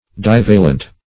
Divalent \Div"a*lent\, a. [Pref. di- + L. valens, valentis, p.